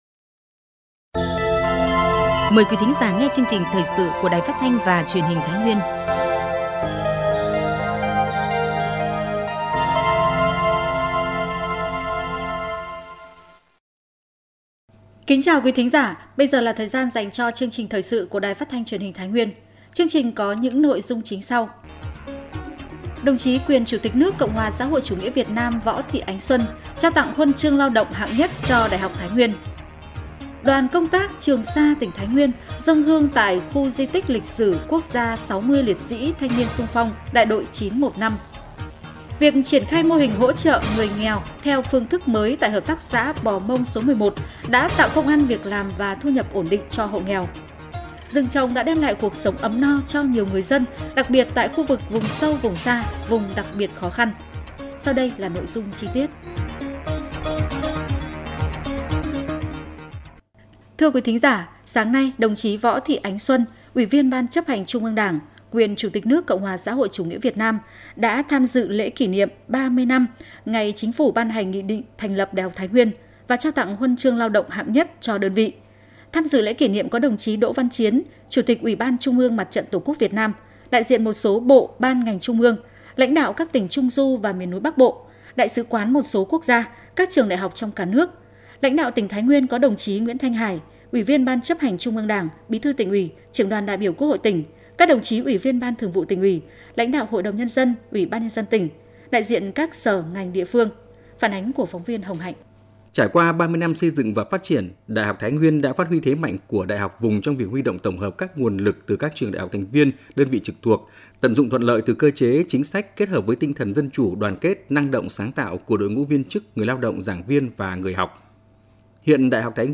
Thời sự tổng hợp Thái Nguyên ngày 13/04/2024